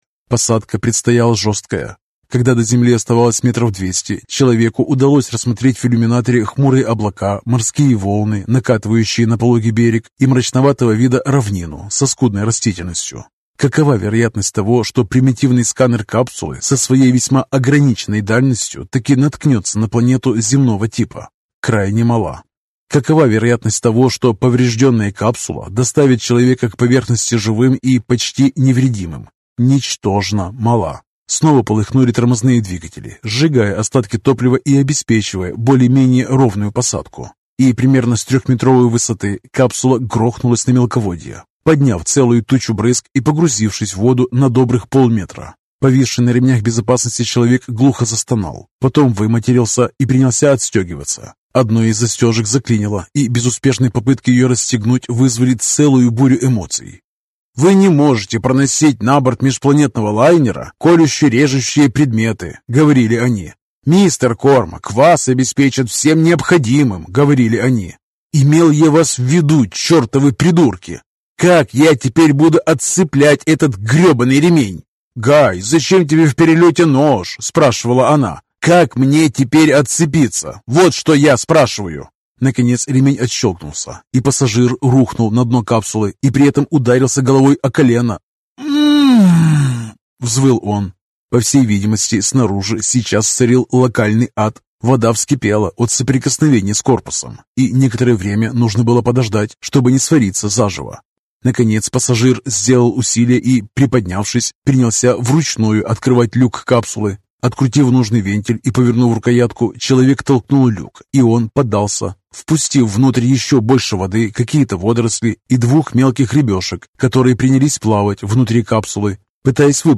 Аудиокнига Что-то новое | Библиотека аудиокниг